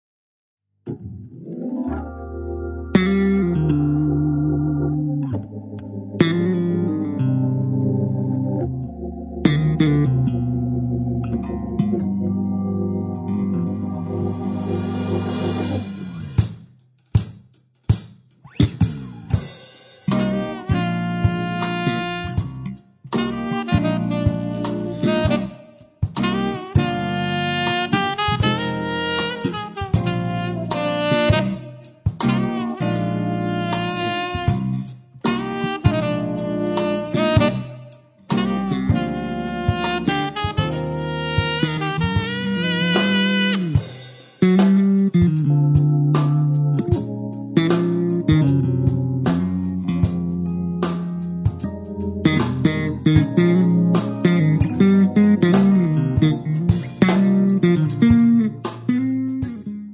chitarra elettrica
sassofoni
basso
batteria
Hammond e tastiere